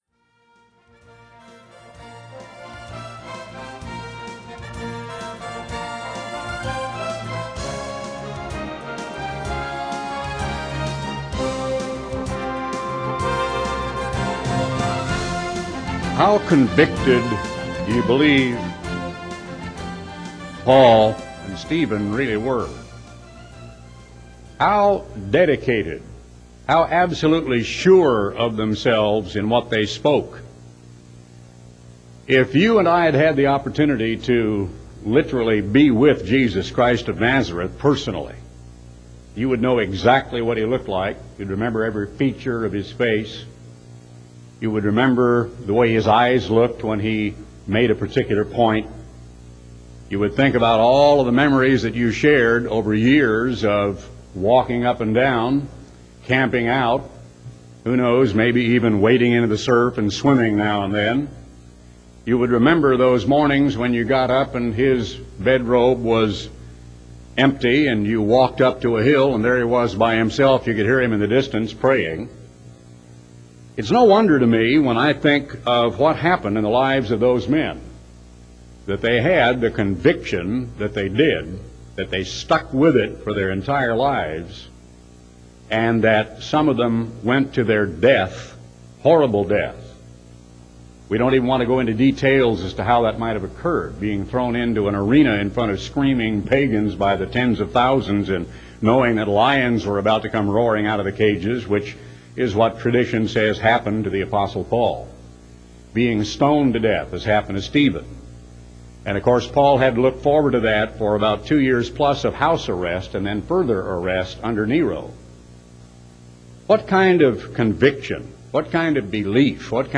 Sermons given by Garner Ted Armstrong in audio format.